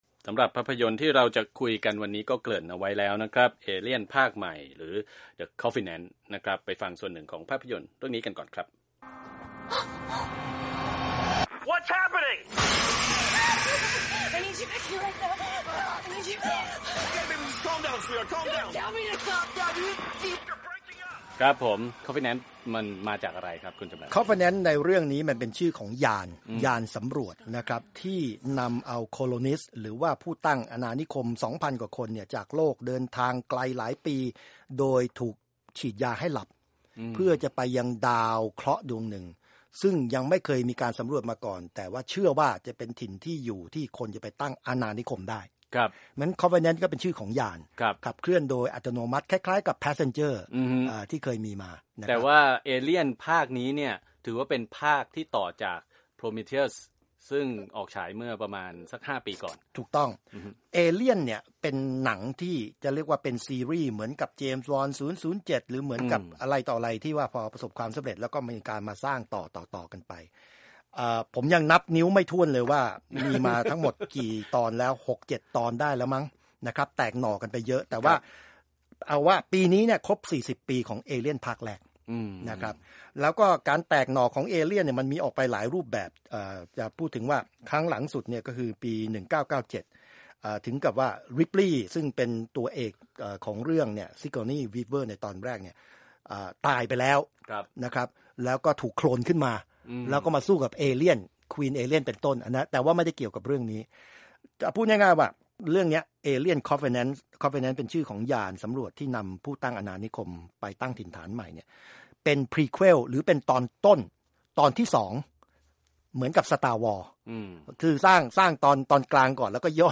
คุยหนัง